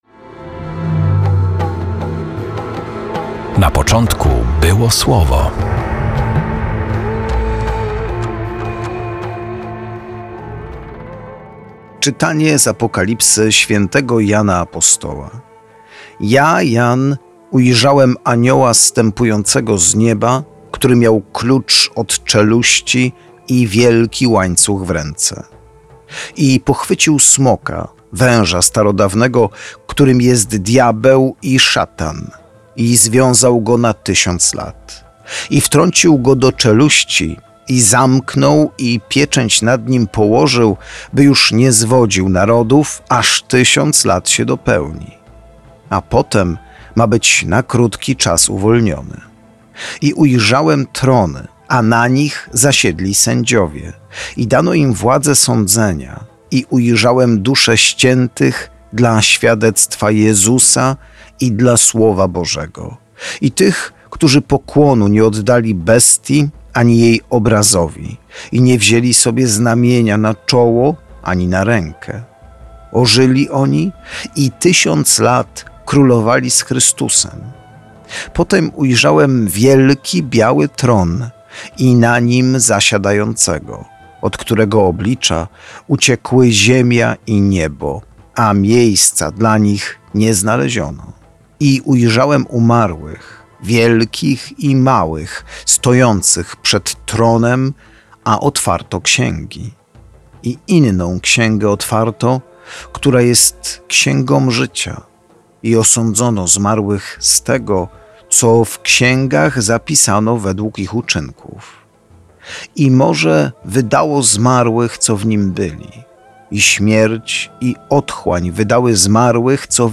Perły ukryte w liturgii słowa odkrywają księża